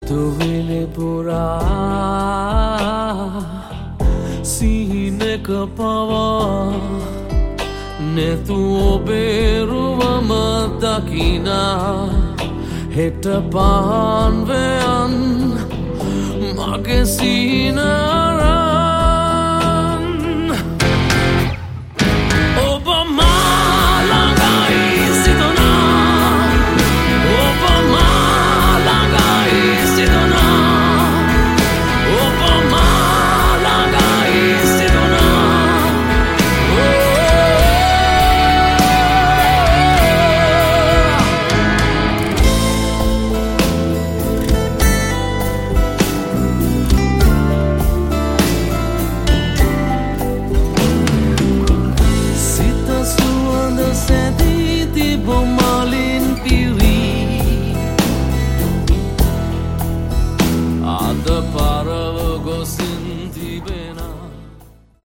Category: Melodic Rock
vocals
guitar
bass
drums